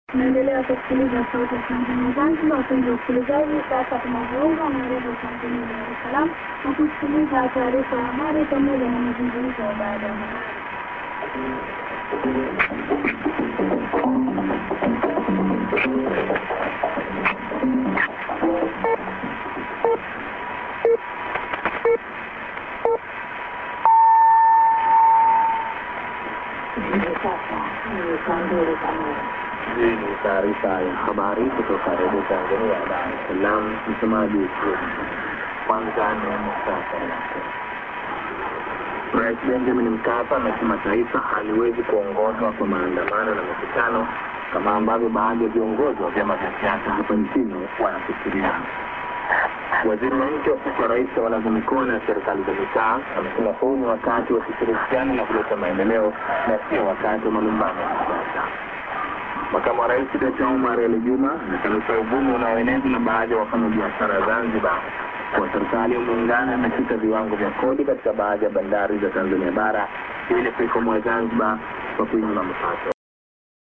ID(women)->durm->TS->ANN(women+man) no ID many word Zanzibar